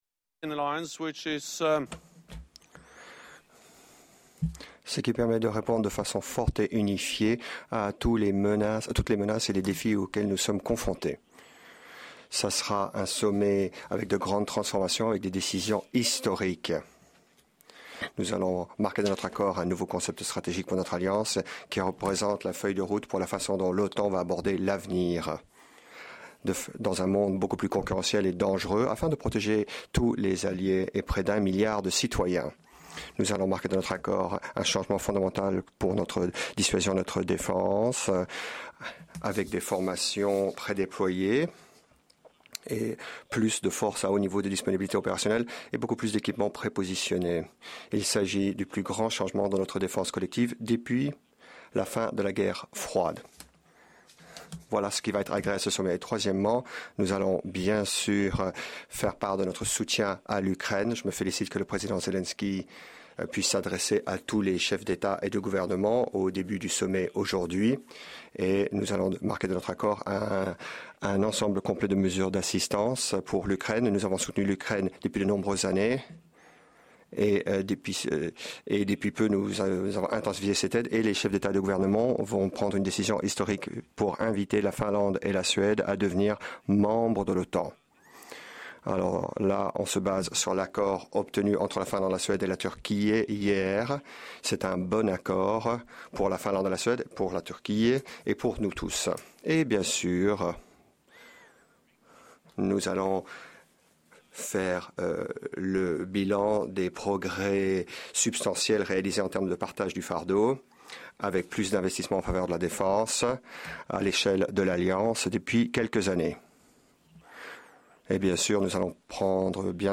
Doorstep statement
by NATO Secretary General Jens Stoltenberg at the start of the 2022 NATO Summit